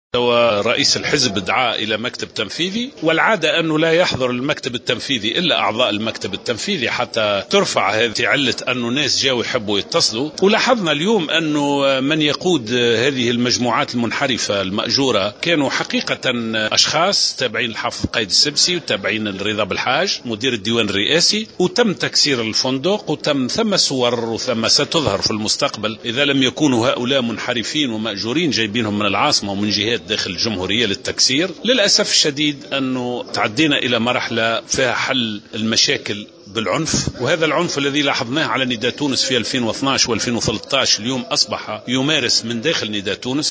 أكد القيادي في حركة نداء تونس لزهر العكرمي على هامش اجتماع المكتب التنفيذي اليوم الأحد أن الحزب وصل إلى مرحلة تعالج فيها المشاكل الداخلية بالعنف وفق قوله.